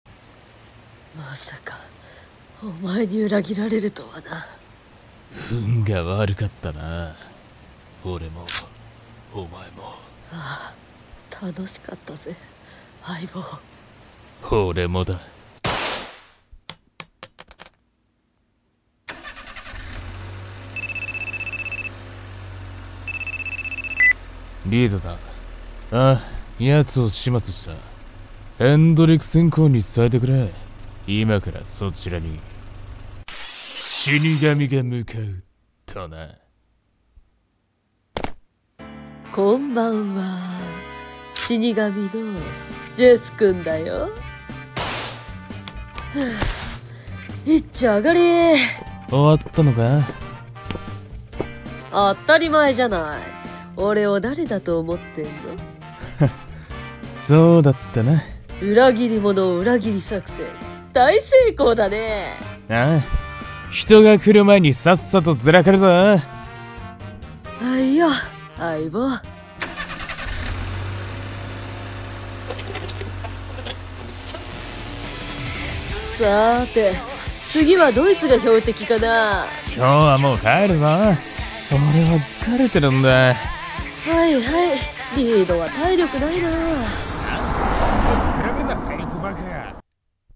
【声劇台本】ヴィランズ・カーニバル【2人用】